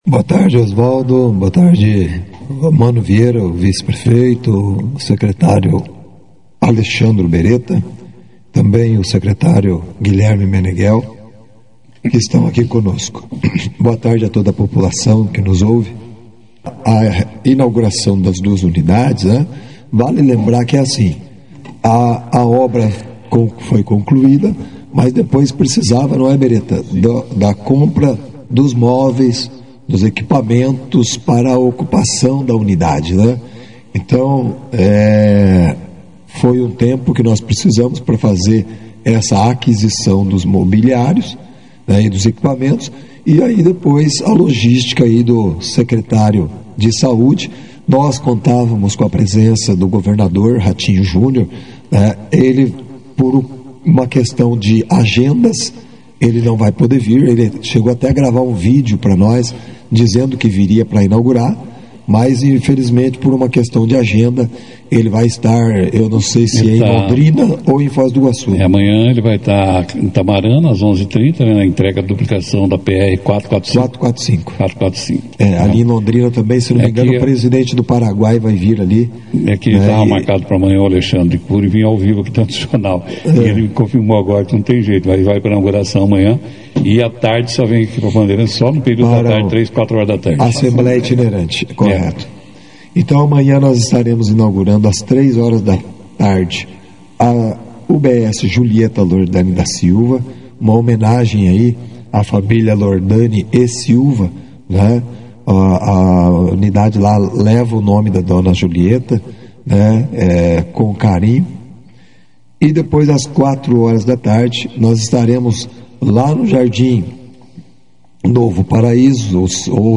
O prefeito Jaleson Matta, o vice-prefeito Mano Vieira, o secretário de Saúde Alexandre Beretta e o novo secretário de Desenvolvimento Econômico Guilherme Meneghel participaram da 2ª edição do jornal Operação Cidade, nesta quarta-feira, 3, falando sobre os novos postos de saúde e de como será o atendimento oferecido.